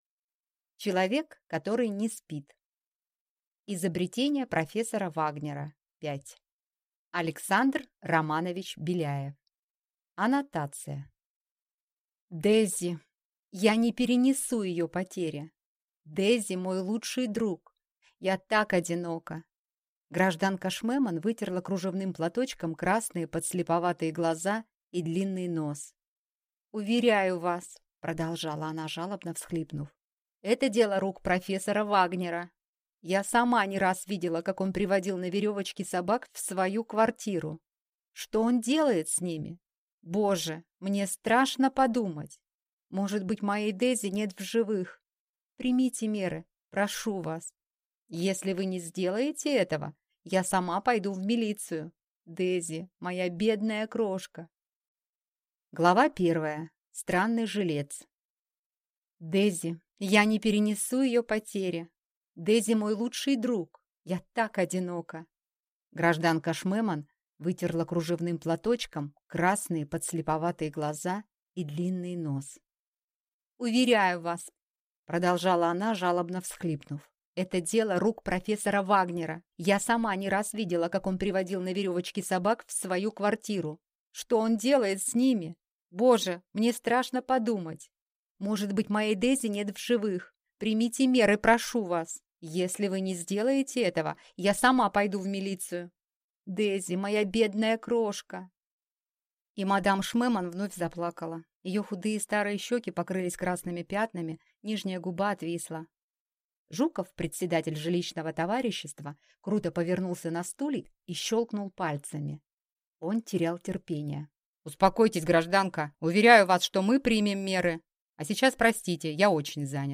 Аудиокнига Человек, который не спит | Библиотека аудиокниг